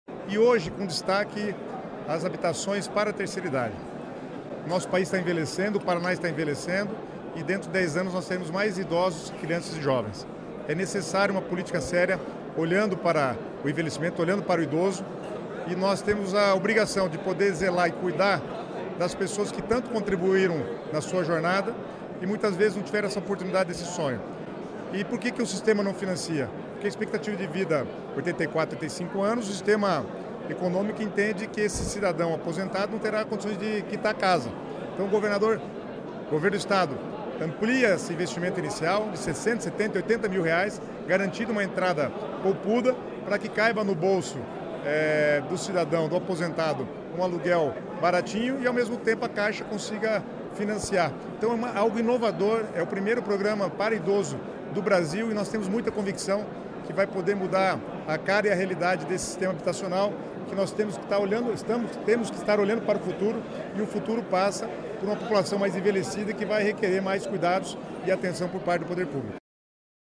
Sonora do secretário das Cidades, Guto Silva, sobre o lançamento do Casa Fácil Paraná Terceira Idade